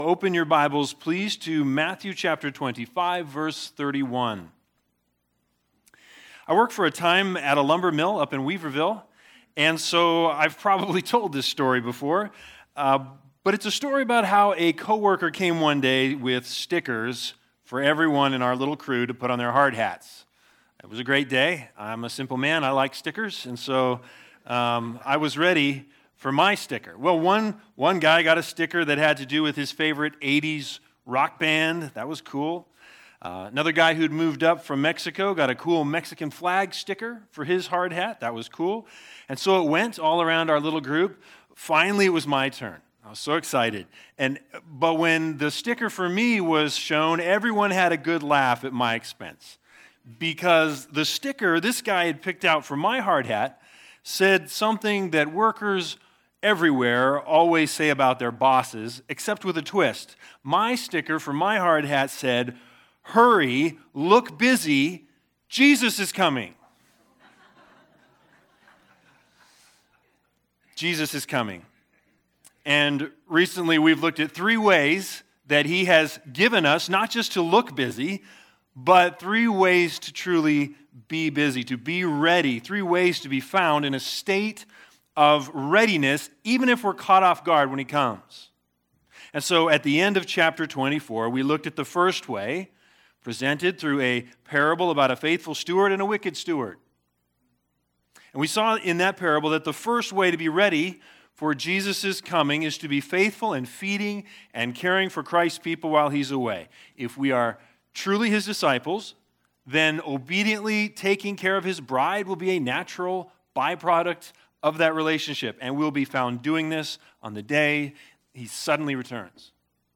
Sunday Sermons BIG IDEA